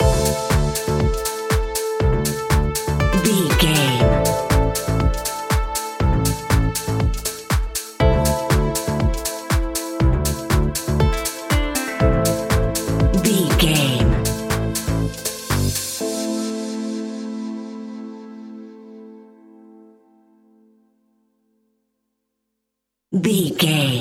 Aeolian/Minor
groovy
uplifting
energetic
repetitive
bass guitar
strings
electric piano
synthesiser
drum machine
funky house
electro
upbeat